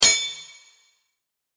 戦闘 （163件）
剣ぶつかり合い4.mp3